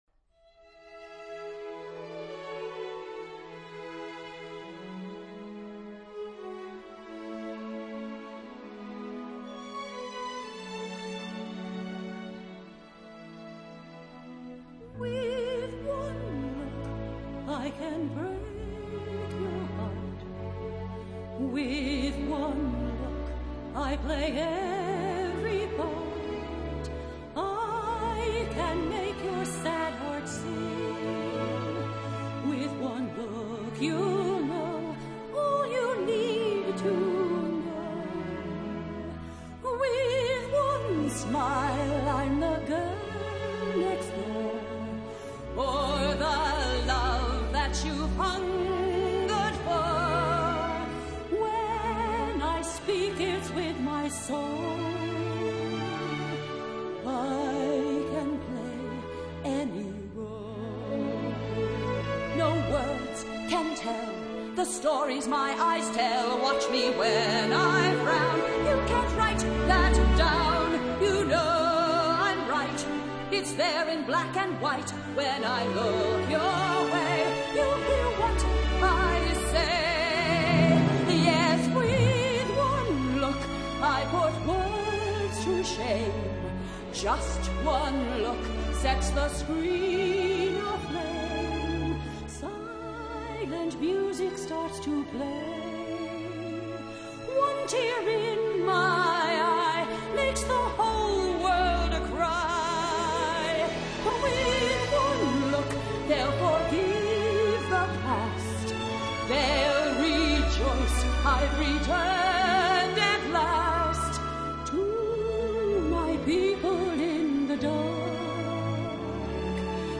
同樣是唯美唱腔與劇劇表現兩者的對比。